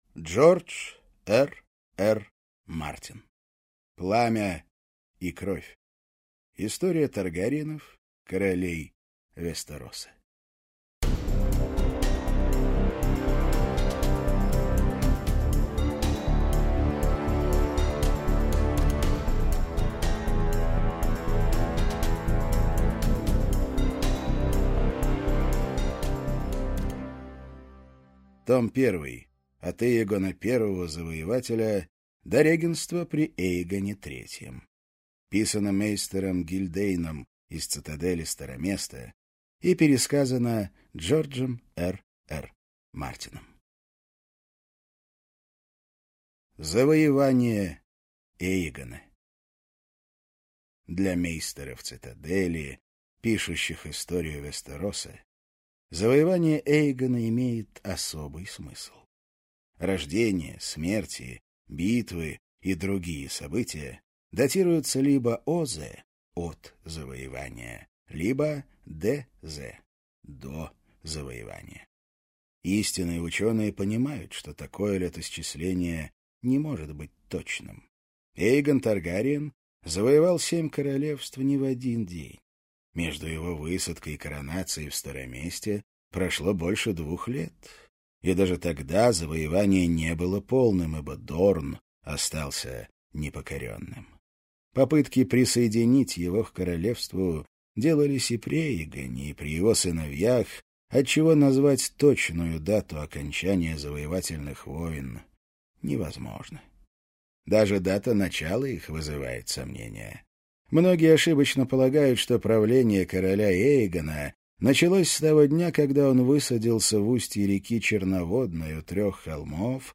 Аудиокнига Пламя и кровь. Кровь драконов - купить, скачать и слушать онлайн | КнигоПоиск